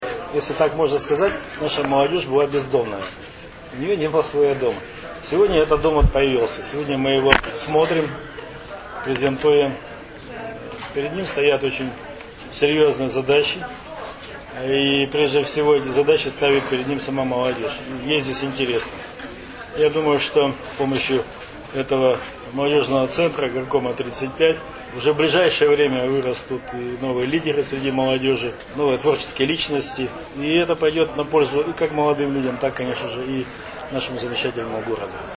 Евгений Шулепов рассказывает о задачах молодежного центра